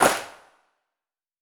kits/Cardiak/Claps/TC3Clap2.wav at main
TC3Clap2.wav